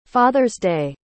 Como pronunciar corretamente Father’s Day?
• Father: /ˈfɑː.ðər/ – o “th” tem som suave, como em “this” ou “that”.
• Day: /deɪ/ – som aberto e claro, tipo um “ei!” animado.